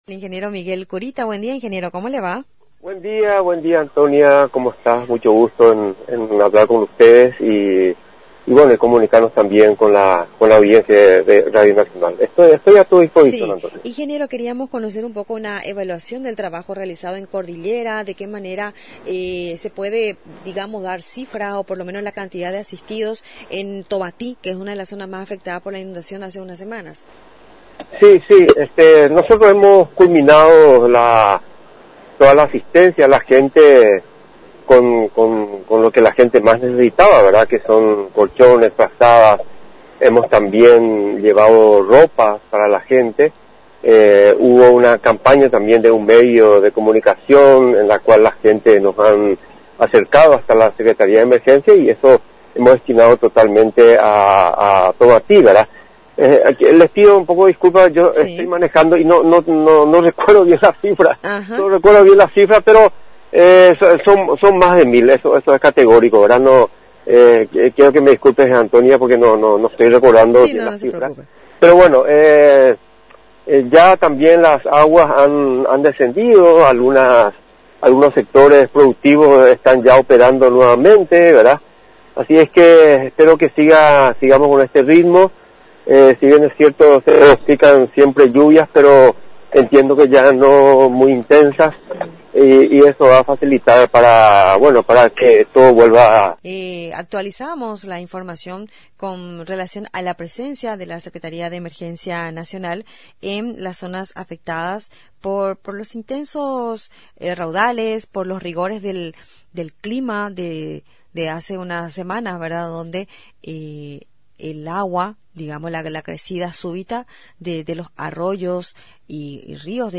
El temporal afectó gran parte de los departamentos de Central, Cordillera, Paraguarí y parte de Caazapá en todos estos puntos estuvimos asistiendo por lo que el trabajo fue intenso en corto día dijo en una entrevista con Radio Nacional del Paraguay, Miguel Kurita, Jefe de Gabinete de la Secretaria de Emergencia Nacional.